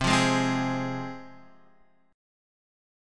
Csus4#5 chord